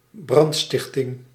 Ääntäminen
IPA: /ɛ̃.sɑ̃.di/